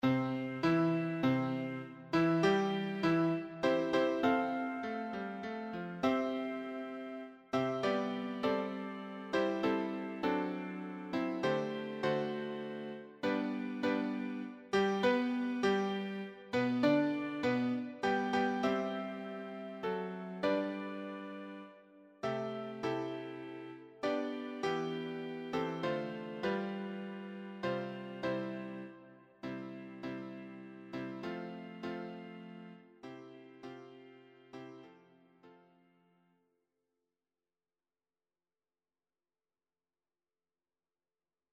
choir SATB
Electronically Generated
Sibelius file